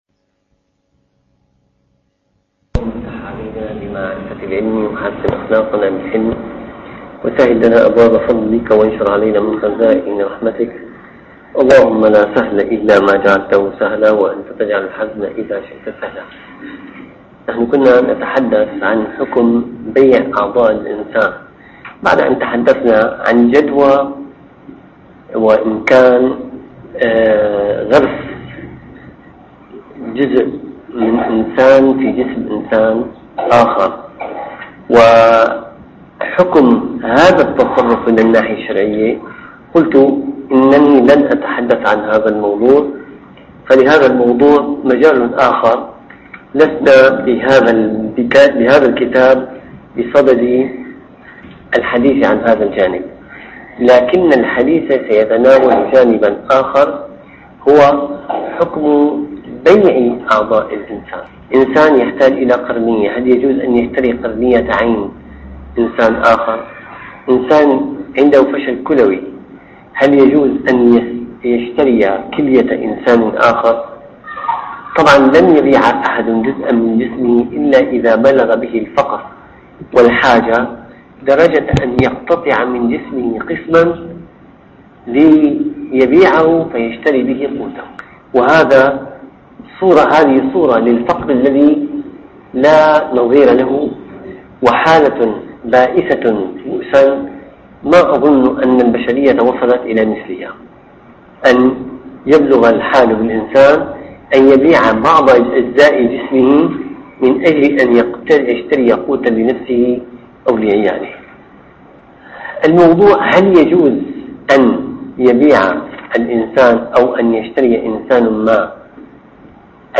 - الدروس العلمية - المعاملات المالية المعاصرة - مسألة بيع أعضاء الإنسان (252-263)